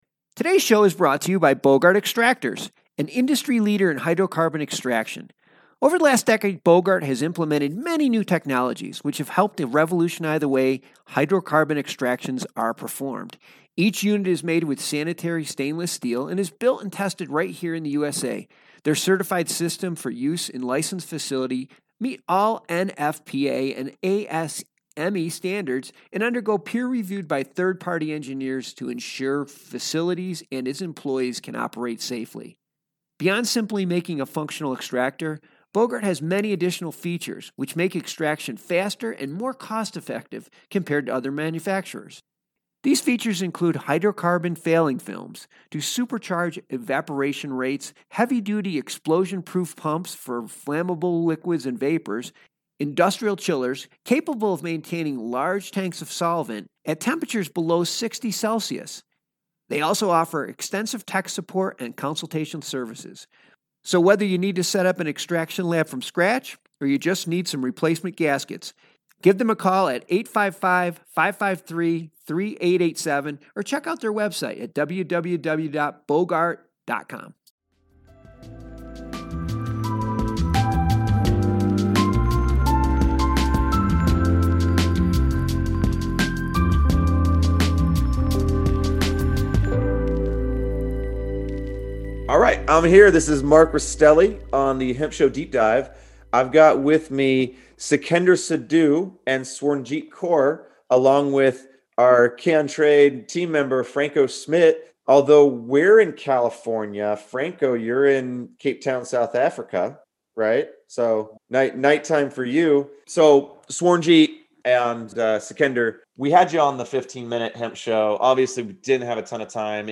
Learn more about the companies whose products are shaping the industry with both short-form and extended "Deep Dive" interviews.